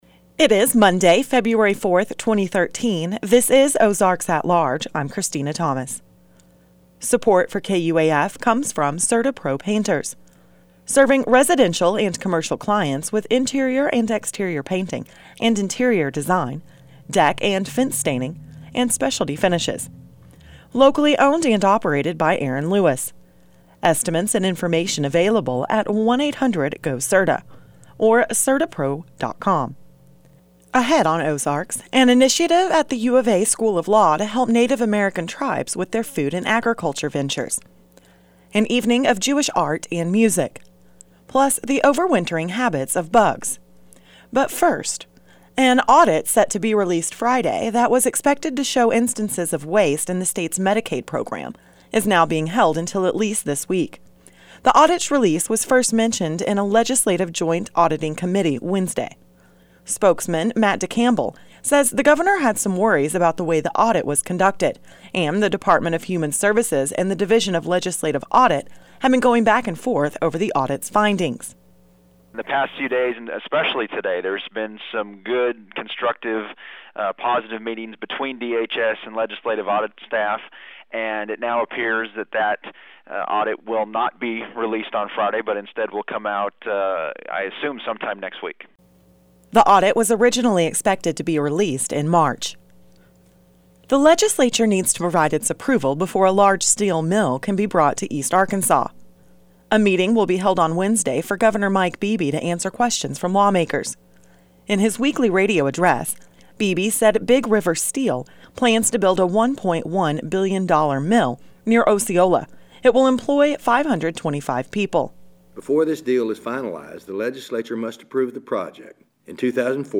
Transition Music: